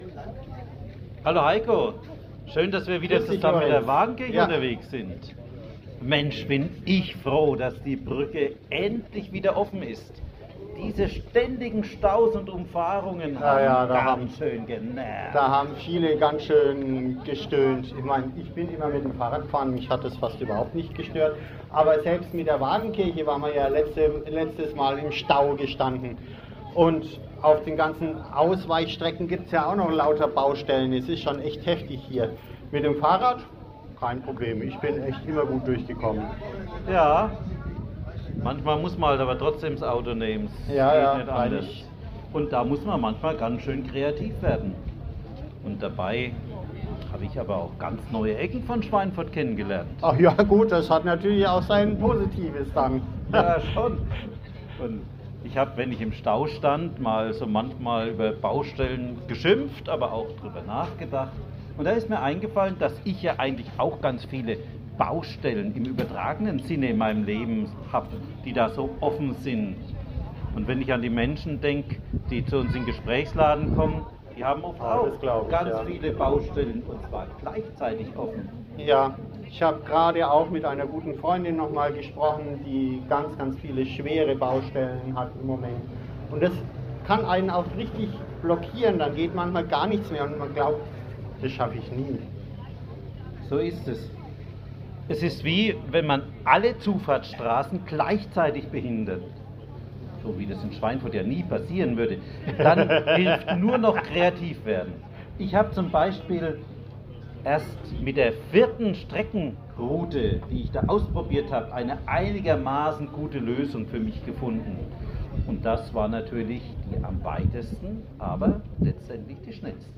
Fußgängerzone. Kurze Impulse zum Nachdenken fürs Wochenende.